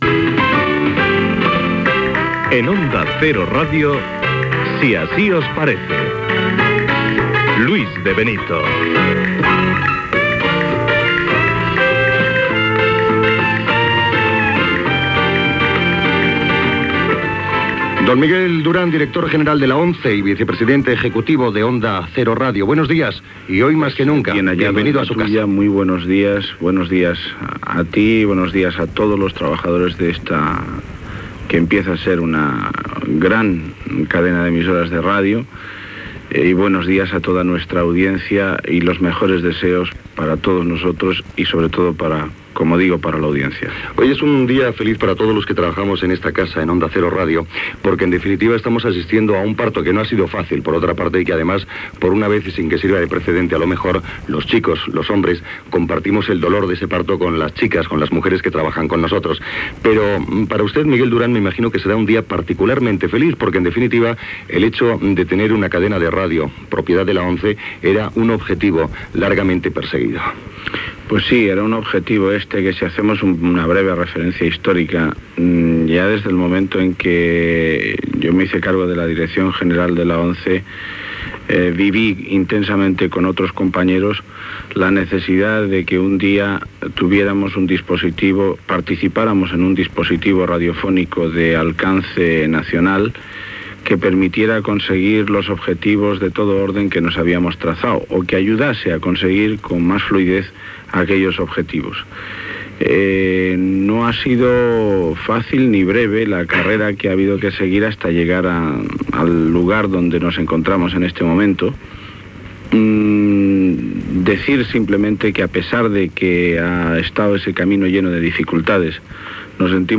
Gènere radiofònic
Informatiu
Emissió inaugural de la cadena propietat de l'ONCE.